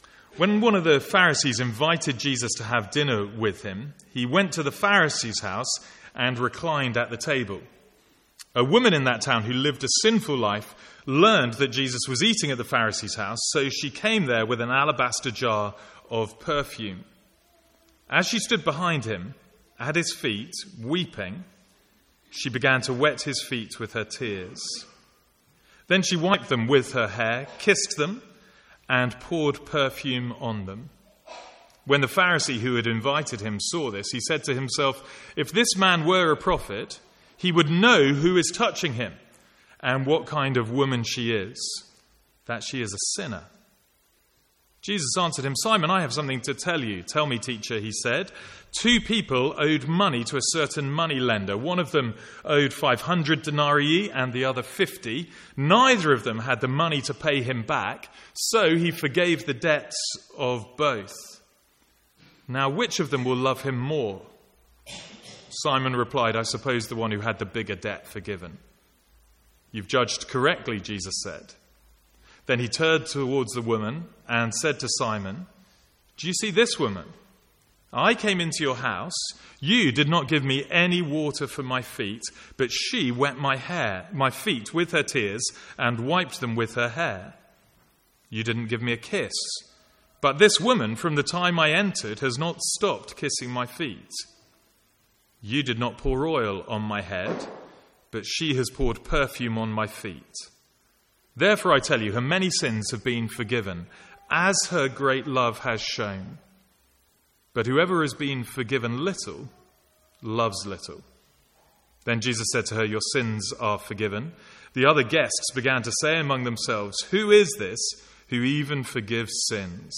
From the Sunday morning series in Luke.